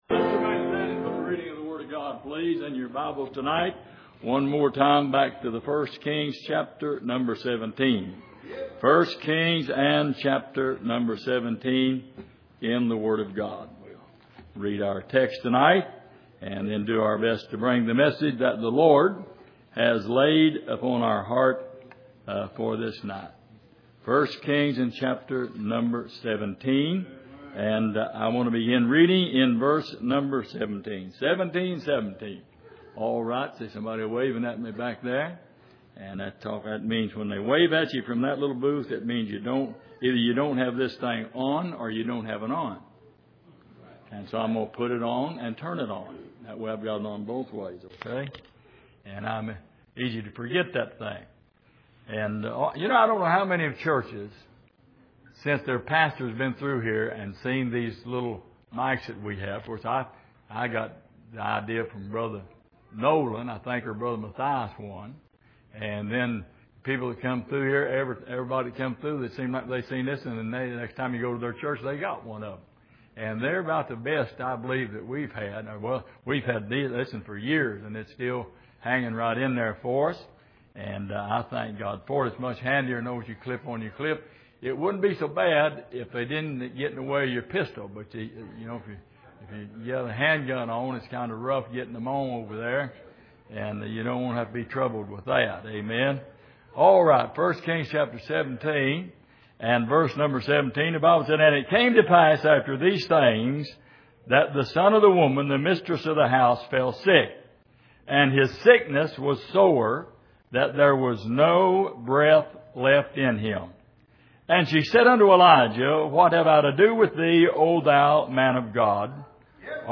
Passage: 1 Kings 17:8-24 Service: Sunday Evening